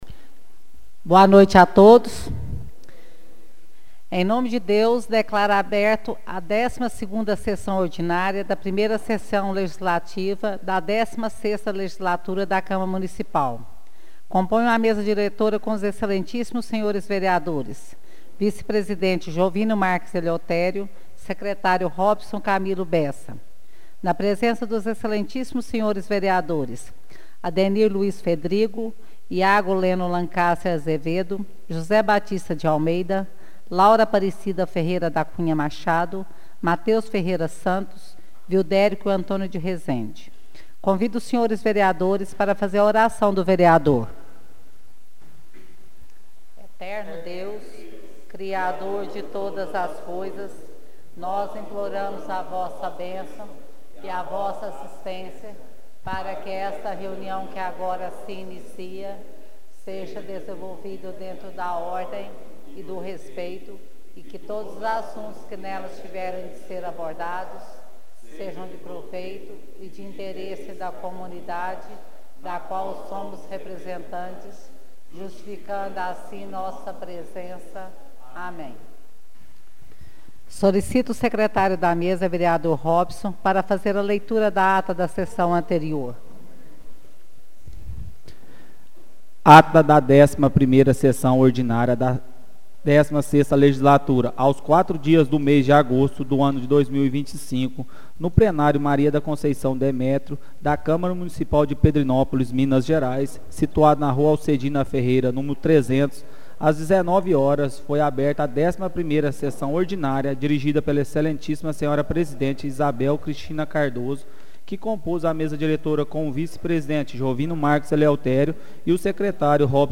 Áudio da 12ª Sessão Ordinária de 2025